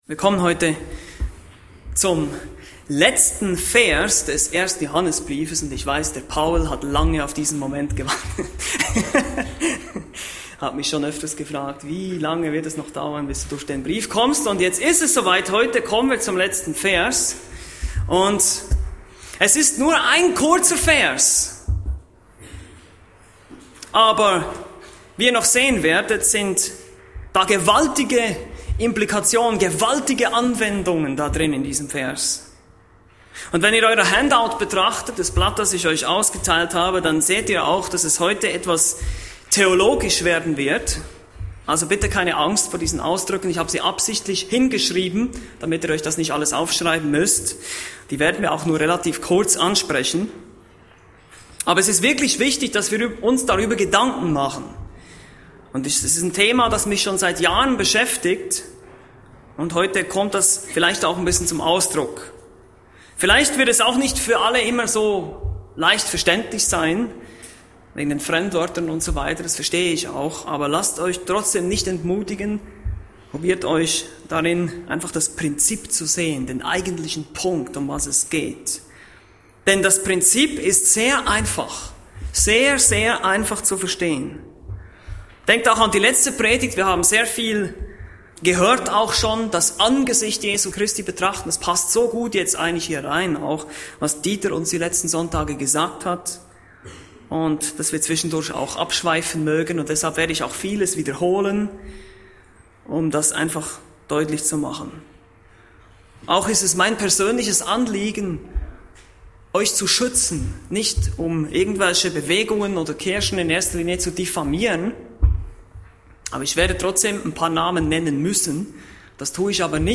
Bibelstunden - Bibelgemeinde Barnim